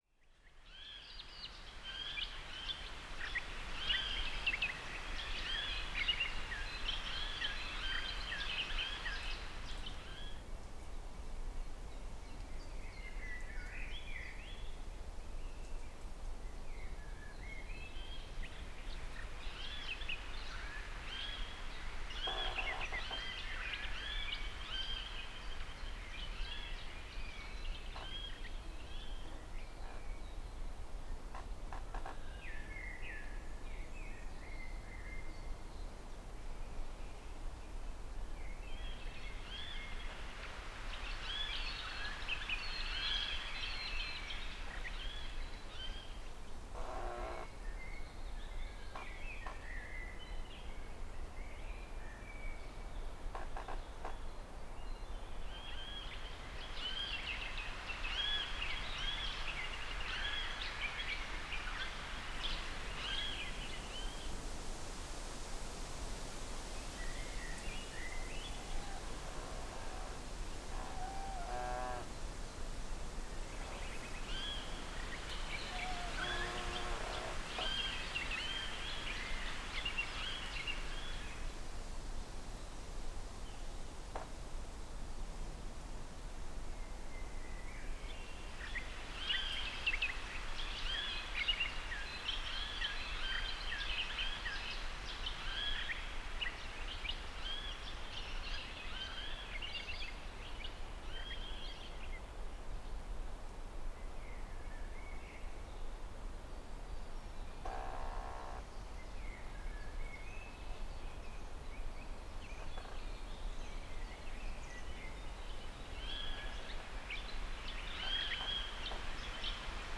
AMB_Scene02_Ambience.mp3